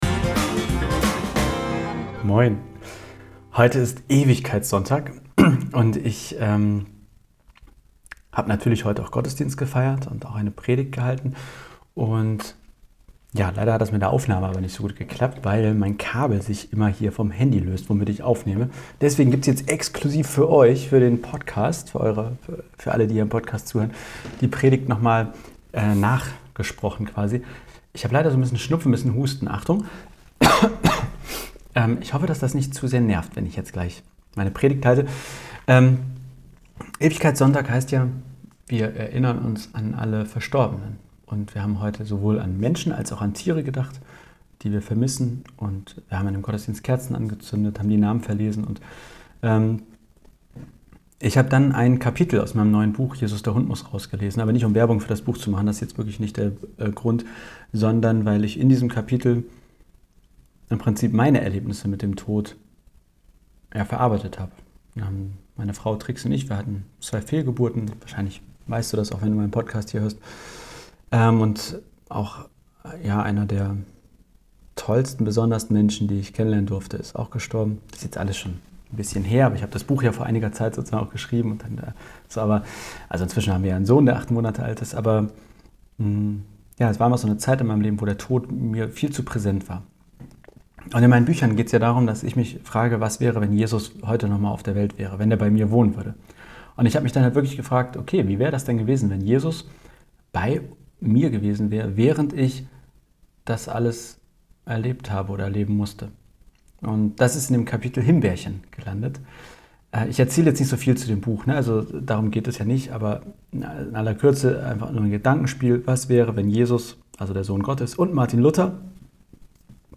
Ein Tag zum Erinnern an all die Menschen und Tiere, die verstorben sind und die wir echt hart vermissen. Meine Predigt zu diesem Tag ist eine Kurzgeschichte. Und es geht um die Frage: was macht Jesus eigentlich, wenn wir trauern?